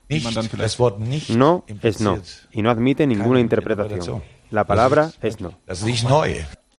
El técnico del Liverpool respondió en rueda de prensa al interés del Barcelona por el jugador brasileño.